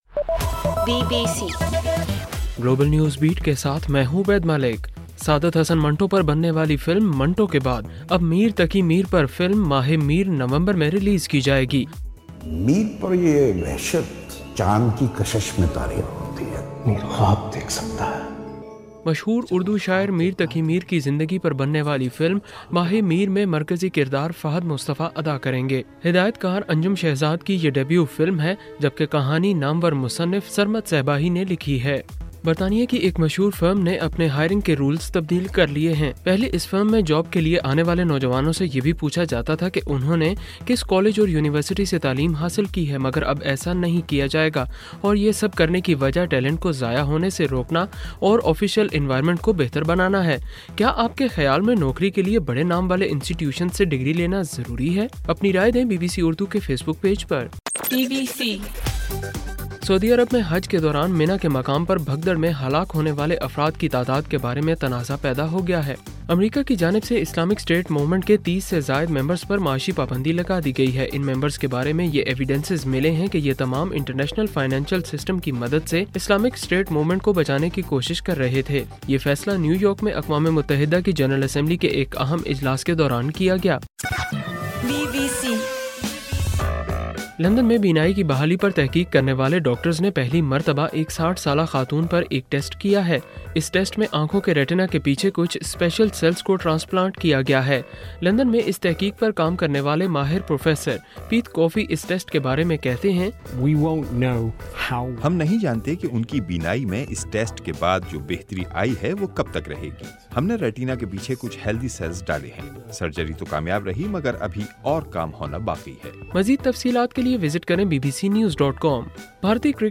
ستمبر 29: رات 12 بجے کا گلوبل نیوز بیٹ بُلیٹن